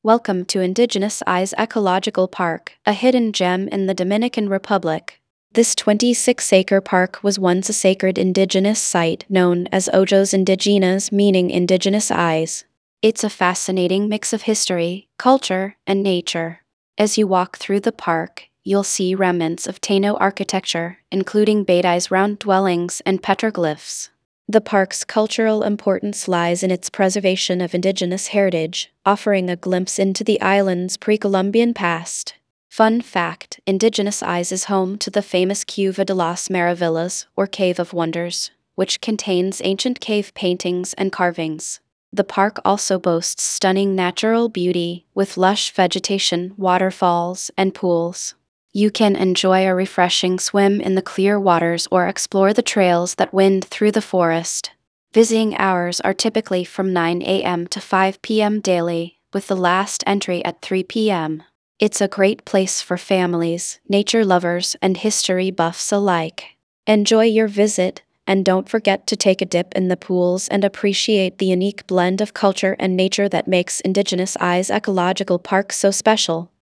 tts